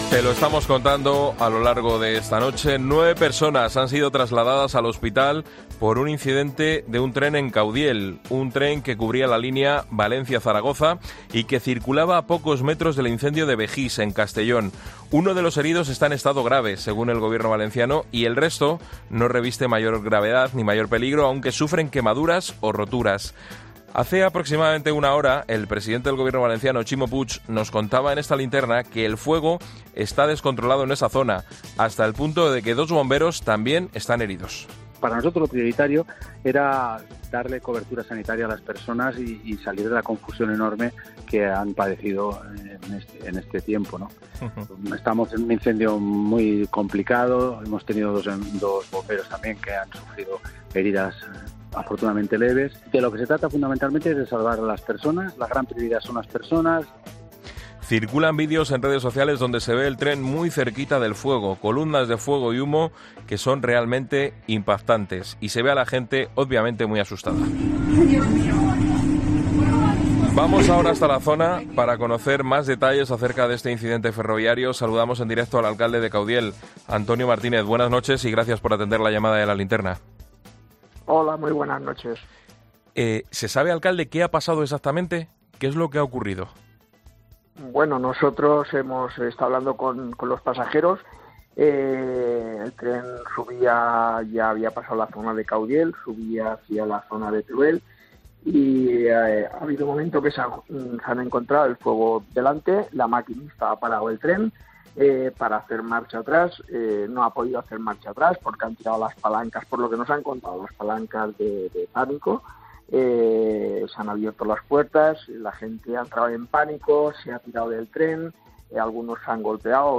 Antonio Martínez, alcalde de Caudiel, explica en COPE la última hora del accidente de tren de la localidad